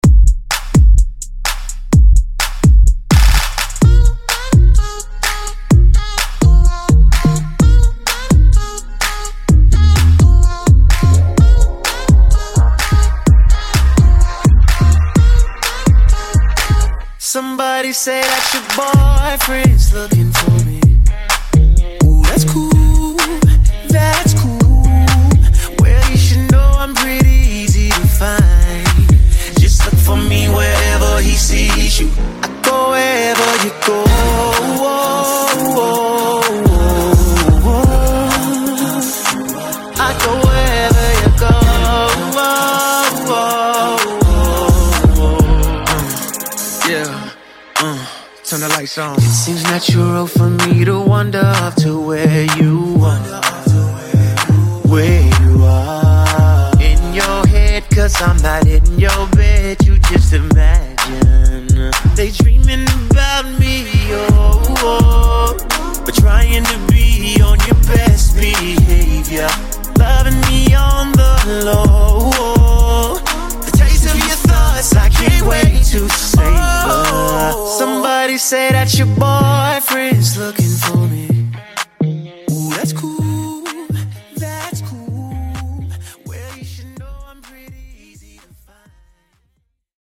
Genre: COUNTRY
Clean BPM: 95 Time